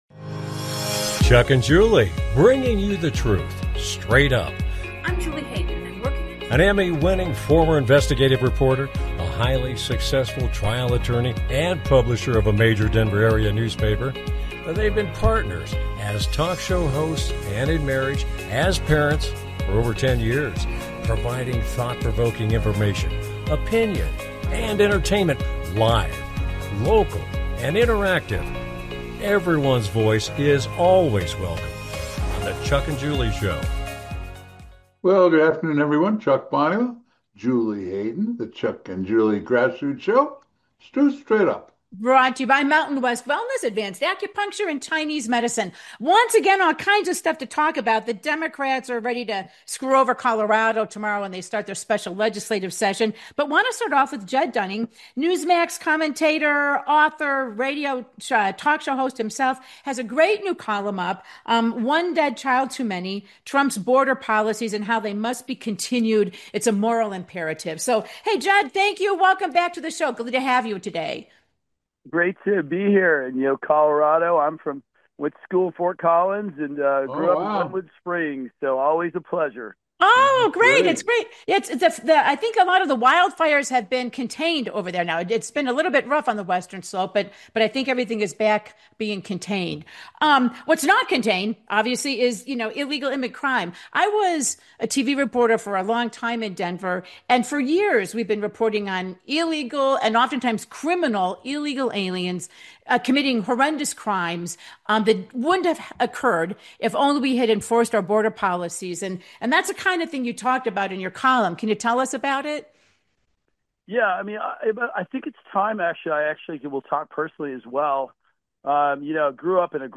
Talk Show Episode, Audio Podcast
Their program is a live Internet call-in talk show providing thought provoking information, conversation and entertainment.